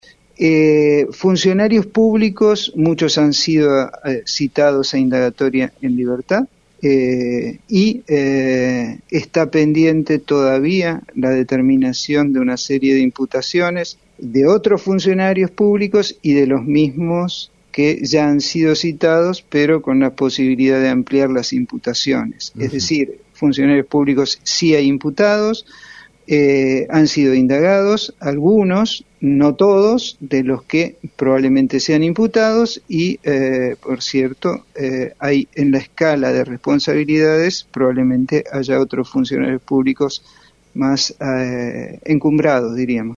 “La asociación ilícita es uno de los puntos claves de las detenciones, además de otra serie de consideraciones vinculadas a los peligros procesales”, indicó el fiscal en en una entrevista radial.